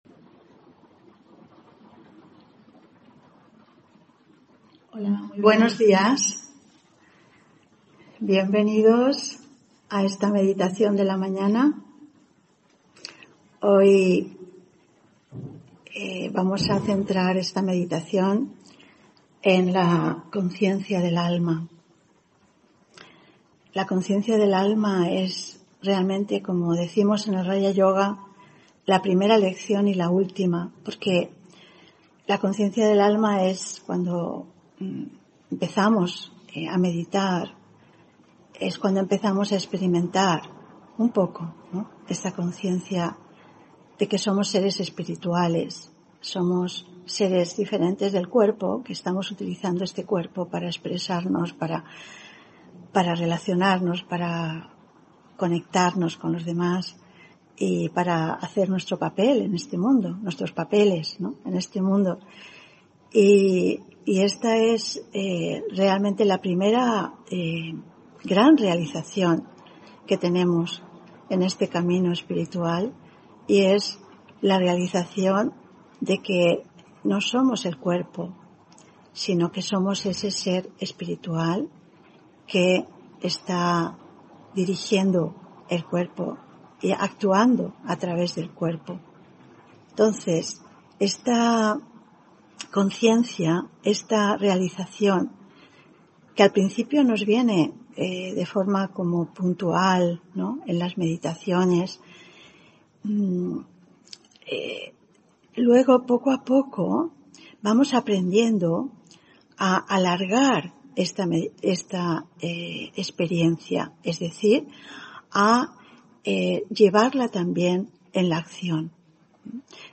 Meditación de la mañana: Conciencia del alma